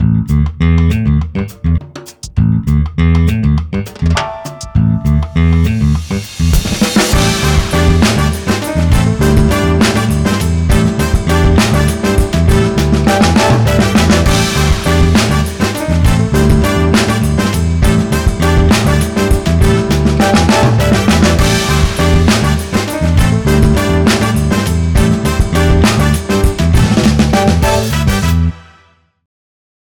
企業VP 都会、派手な雰囲気をイメージしたベースとブラスが際立つクールなジャズファンク。
トランペット
パワフル , オープニング , アグレッシブ